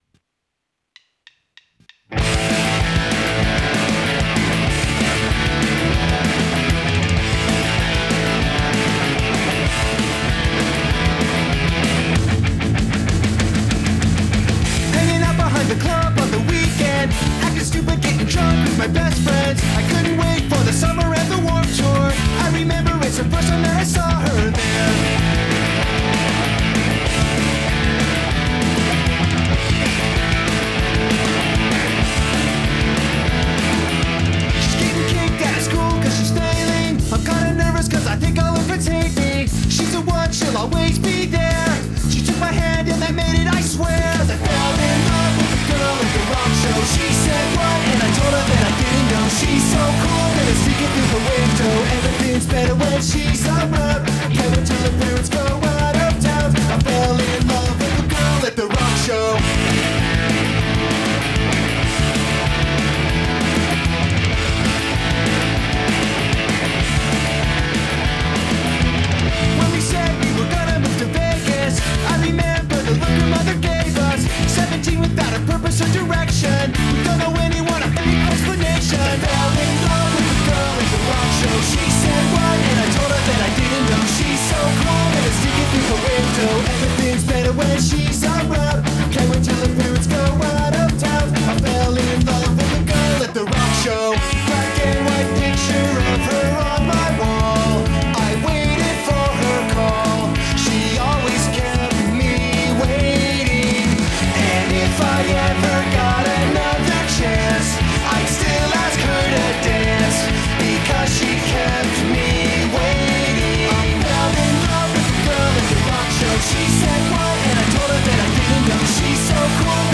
I thought it sounded great.